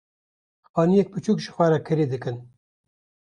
Pronounced as (IPA)
/pɪˈt͡ʃuːk/